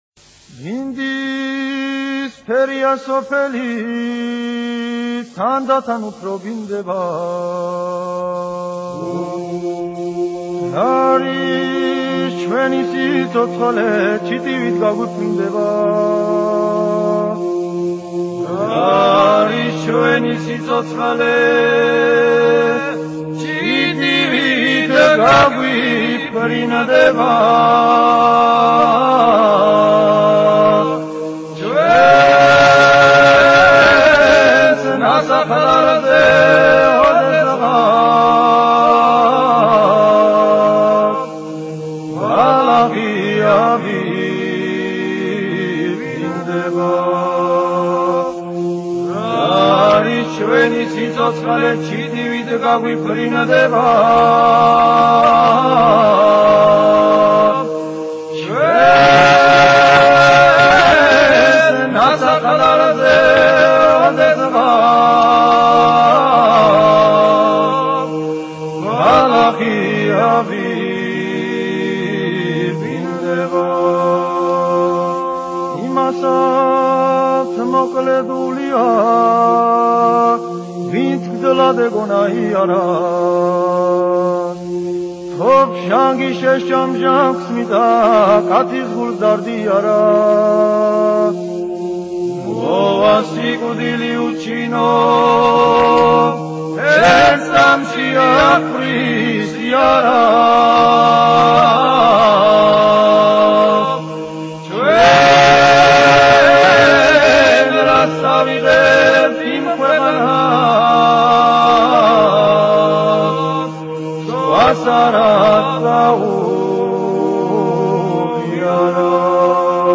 ხალხური